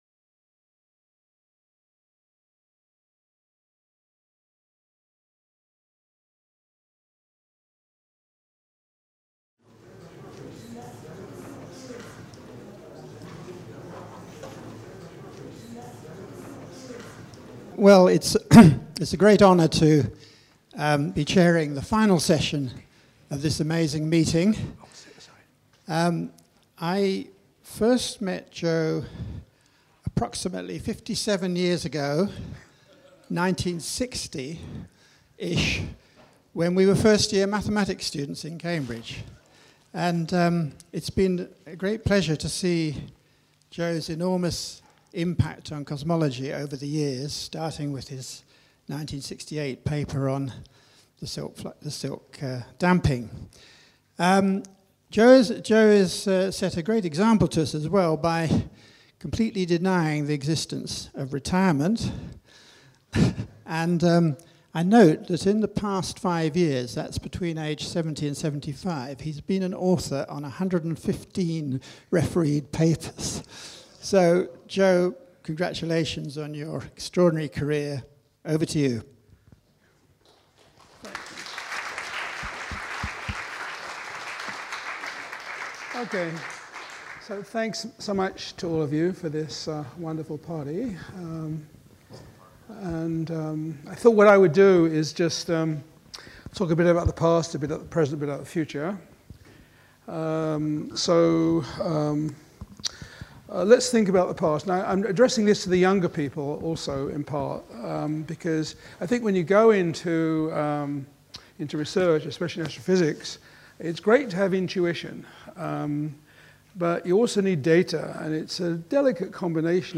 This video shows Joe Silk's conclusion of the conference, emphasizing about the history of cosmology, his career, and questionning the future.